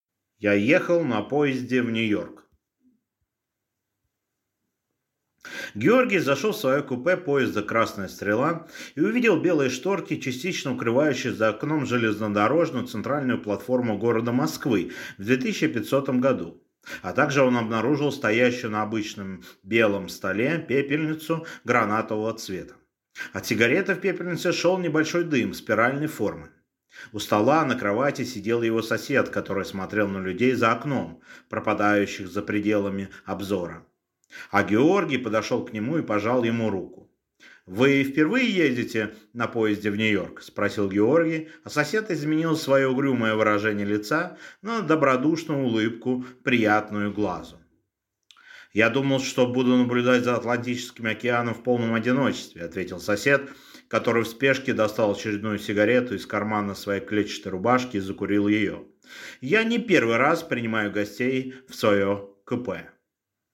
Аудиокнига Я ехал на поезде в Нью-Йорк | Библиотека аудиокниг